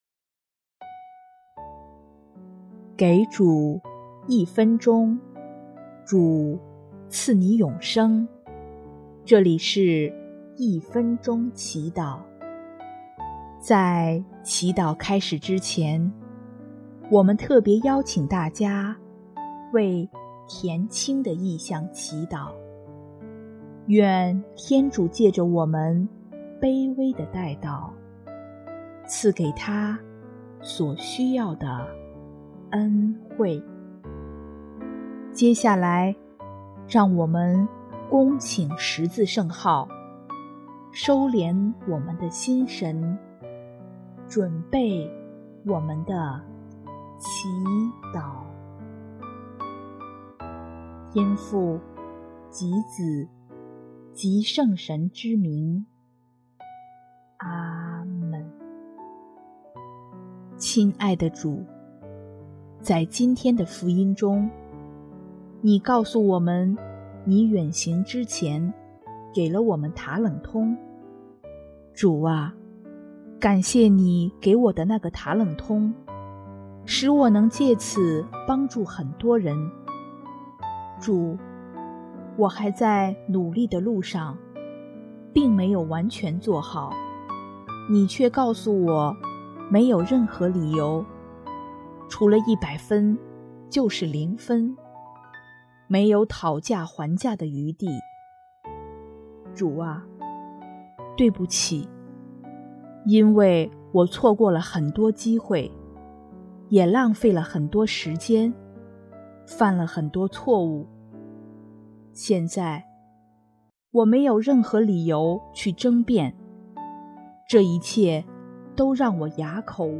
【一分钟祈祷】|9月2日 善用“塔冷通”，得天堂福乐